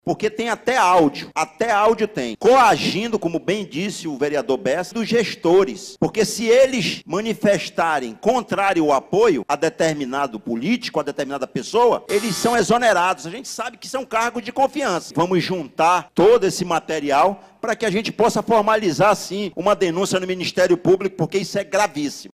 Os vereadores da Câmara Municipal de Manaus batem boca e trocam farpas durante sessão plenária nesta terça-feira, 27.
Durante discurso, o vereador Everton Assis (UB) sugeriu apresentar as denúncias dos parlamentares ao Ministério Público.